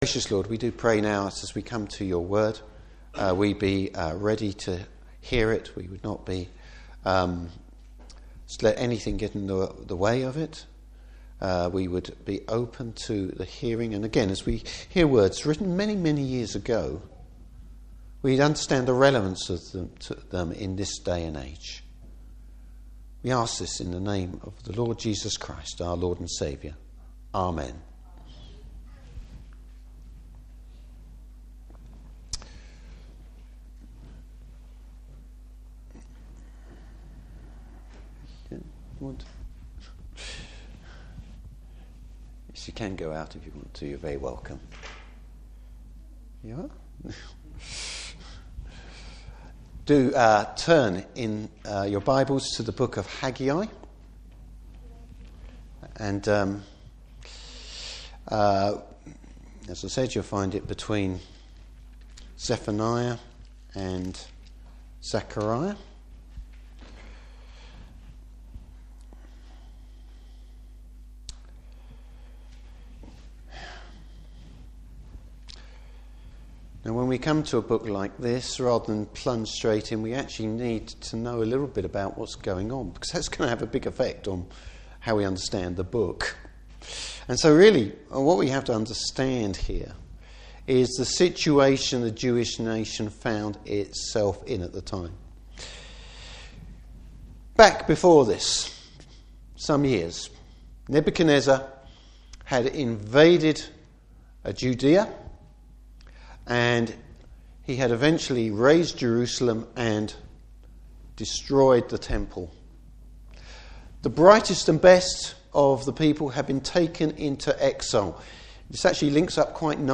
Service Type: Morning Service Getting spiritual priorities right.